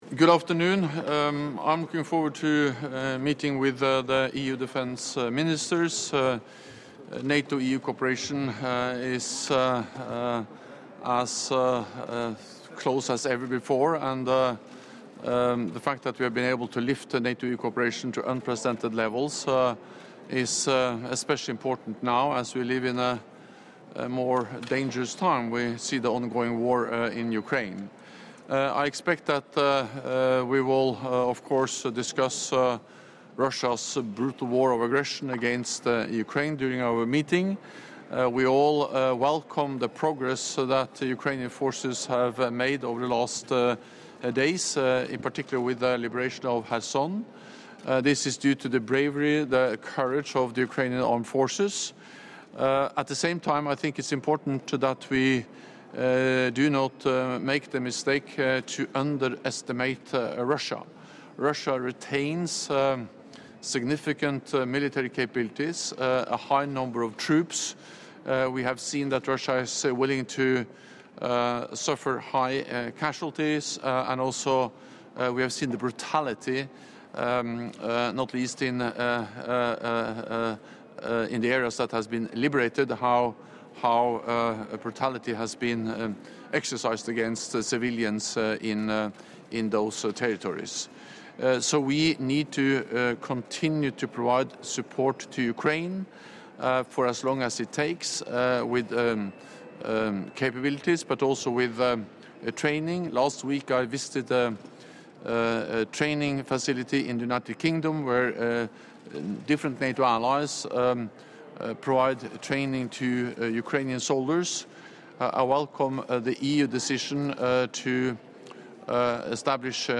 Doorstep statement by NATO Secretary General Jens Stoltenberg ahead of the EU Foreign Affairs Council in Defence format
(As delivered)